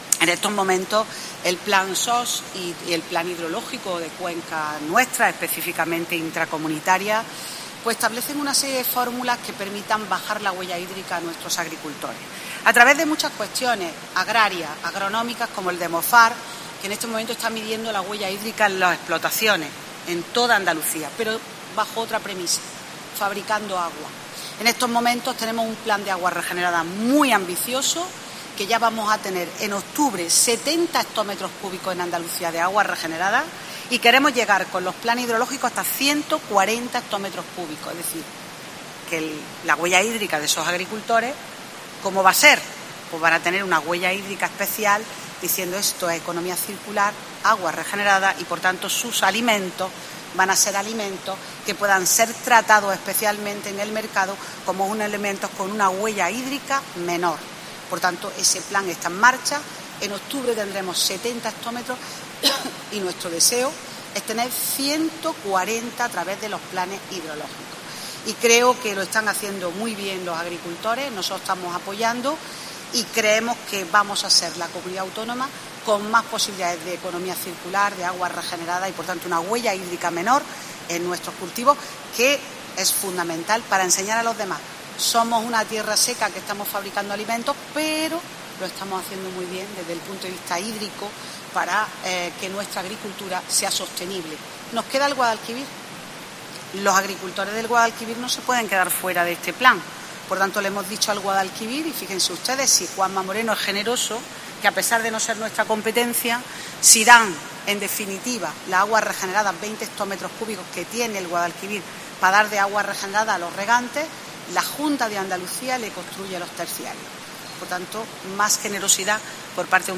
Así lo ha manifestado Crespo a preguntas de los periodistas en Córdoba, donde, acompañada por el alcalde, José María Bellido, ha presentado el proyecto de abastecimiento del Cerrillo a Cerro Muriano, subrayando que tanto el Plan SOS y como el Plan Hidrológico "establecen una serie de fórmulas que permiten bajar la huella hídrica de nuestros agricultores".